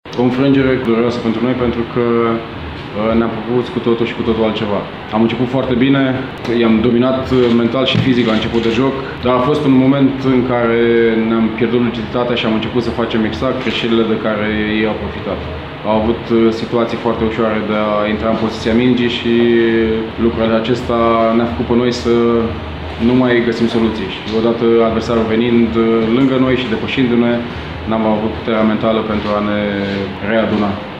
Declaraţiile finalului de meci: